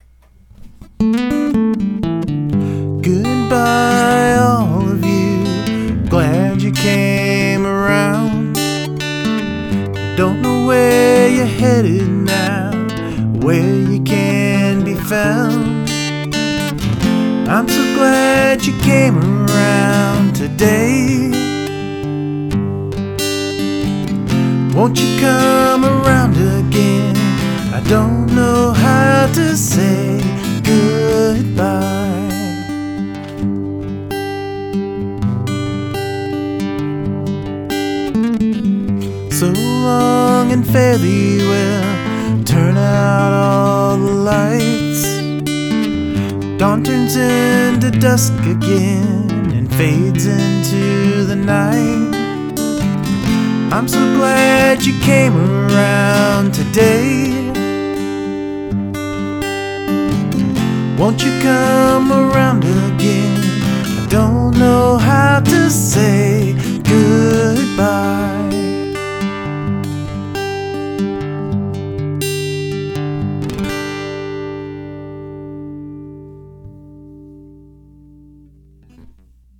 I think the verses fit well with the music on this one.
Great song, your singing is very good here, sweet almost.  Great guitar lines there.
Great vox!
Simple and bittersweet.